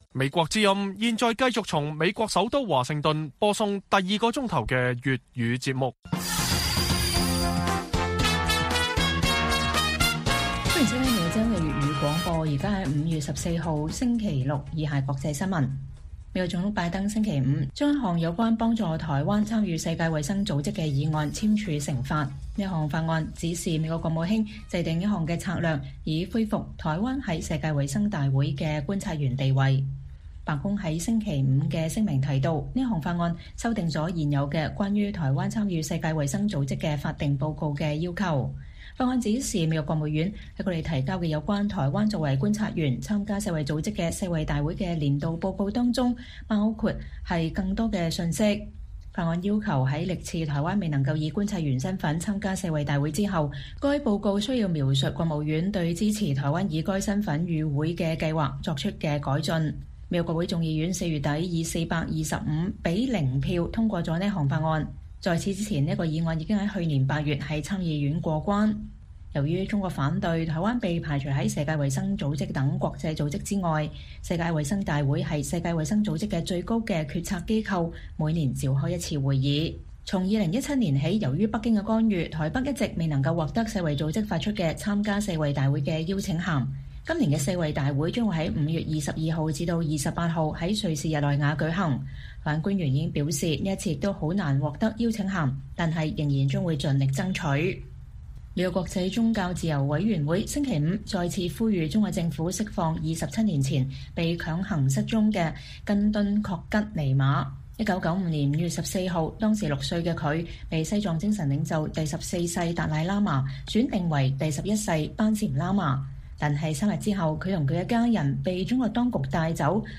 粵語新聞 晚上10-11點：拜登總統將美國幫助台灣參加世衛大會議案簽署成法